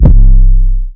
14 808 -plugg.wav